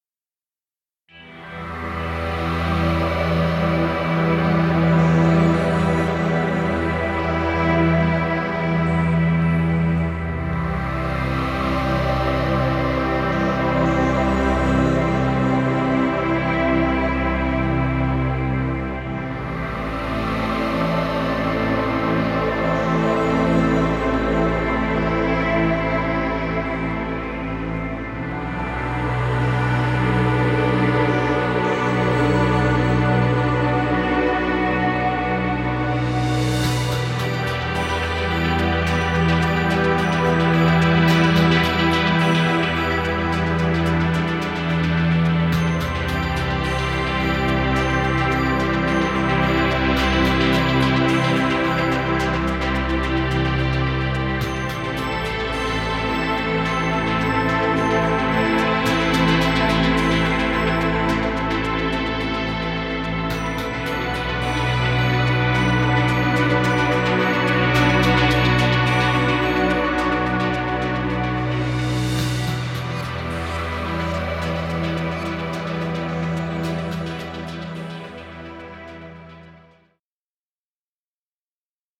Ambient music. Background music Royalty Free.